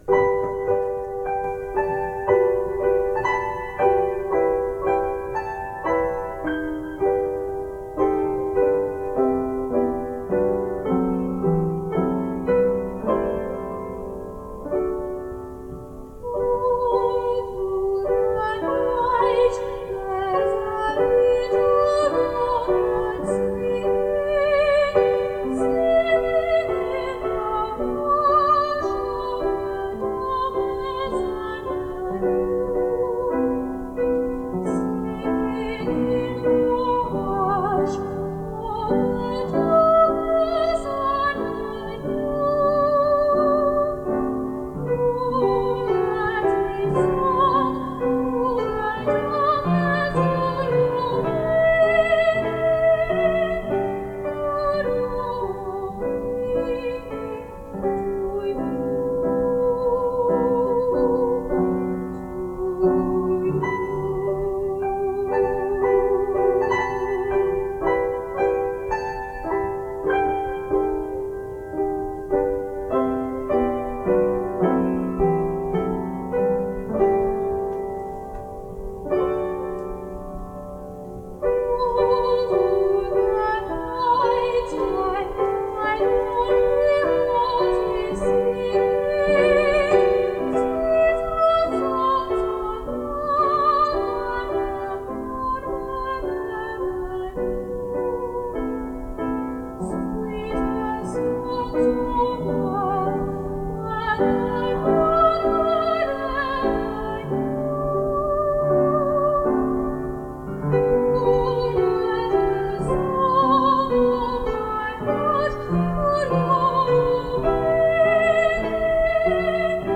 I am a soprano.
(Listen out for the green singing finch in the back (and often fore) ground. He used to sit beside the piano and join in!)
Please accept my apologies for the often inadequate piano playing, and the unprofessional quality of recording.